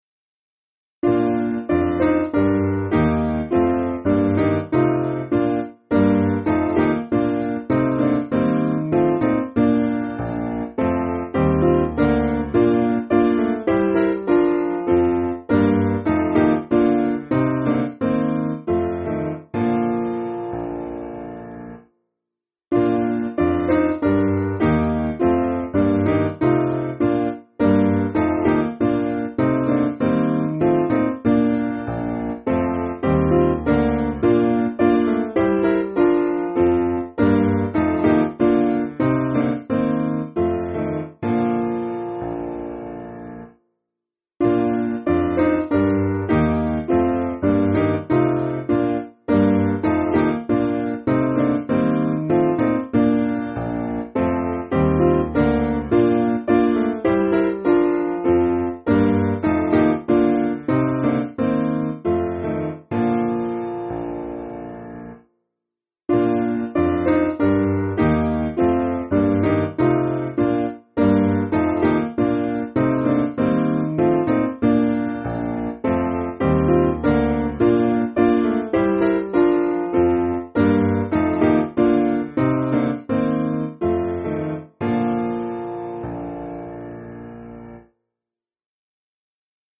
Simple Piano
(CM)   4/Bb